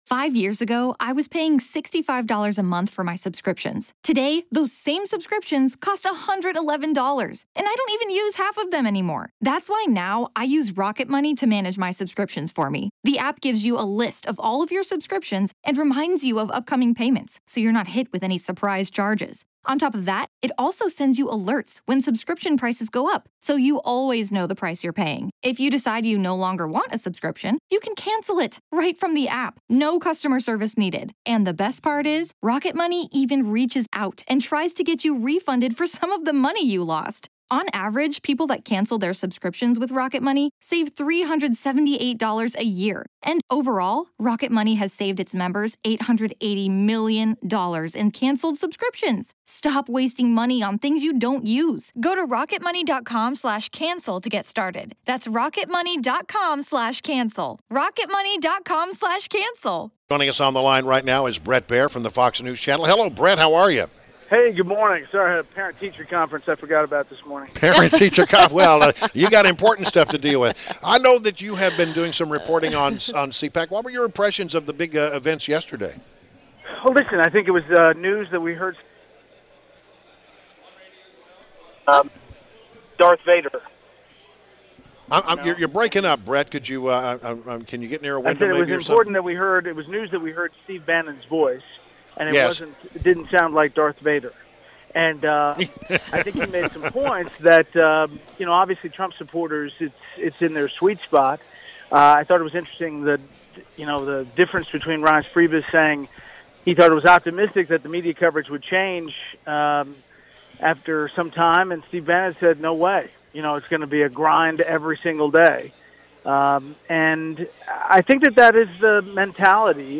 WMAL Interview - BRET BAIER - 2.24.17